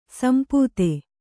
♪ sampūte